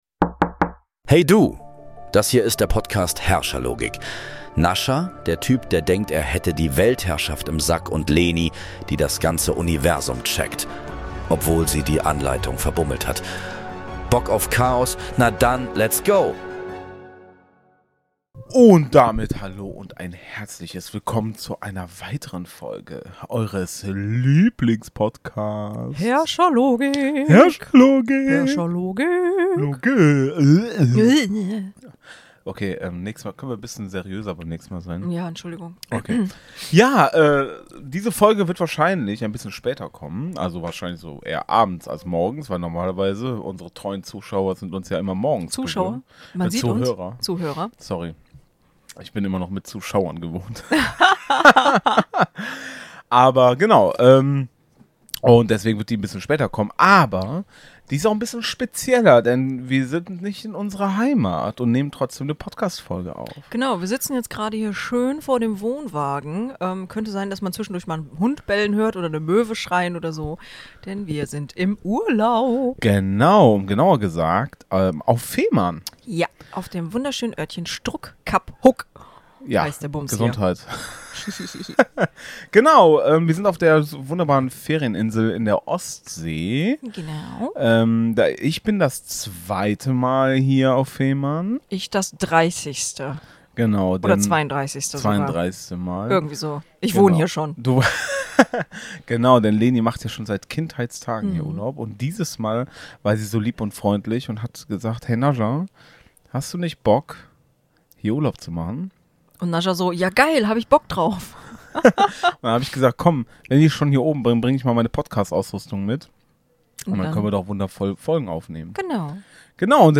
Beschreibung vor 7 Monaten Level 46 – Fehmarn  Urlaubsvibes direkt vom Campingplatz!